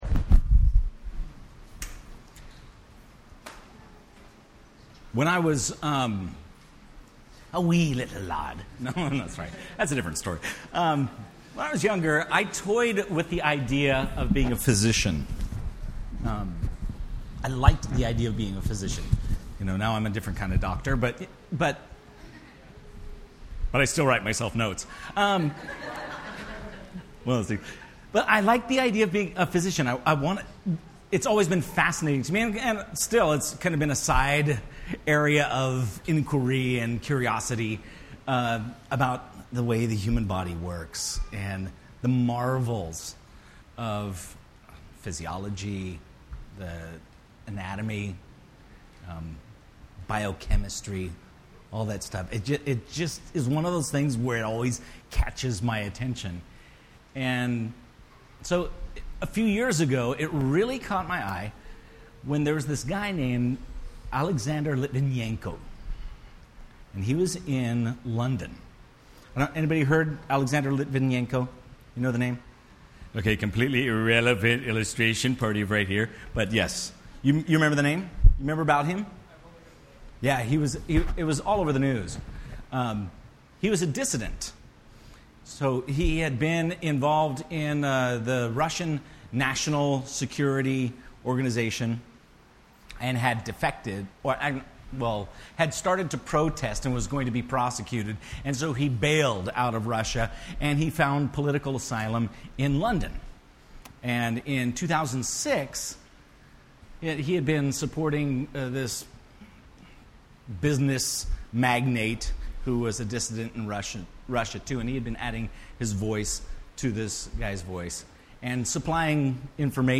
The Core Service Type: Sunday Morning %todo_render% Related « The Core